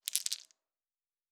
Dice Shake 3.wav